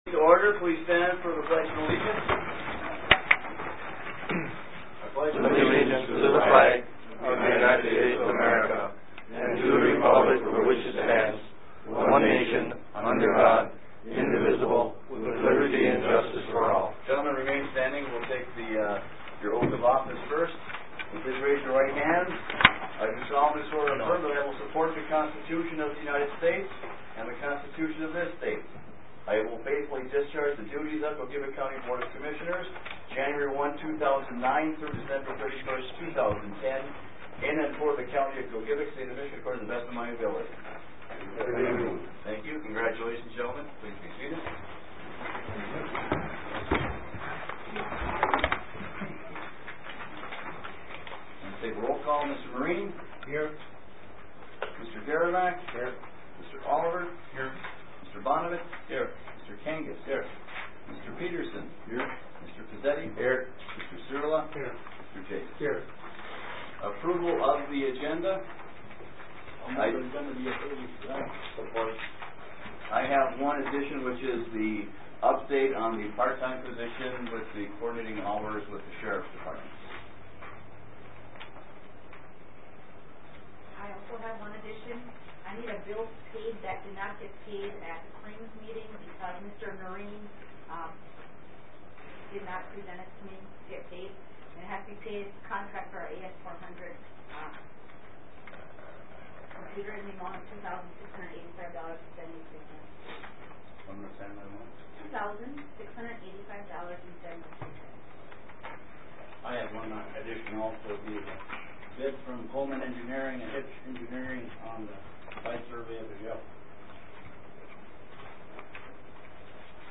Gogebic County Commissioners Hold First Meeting of 2009
Last evening the Gogebic County Board of Commissioners held a special organizational meeting for 2009. Following the Pledge of Allegiance, Gerry Pelissero Clerk of Court, administered the Oath of Office to the incoming Board of Commissioners.